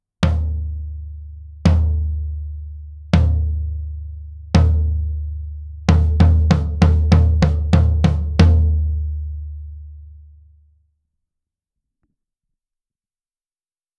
実際の録り音
フロアタム
58ドラムフロアタム.wav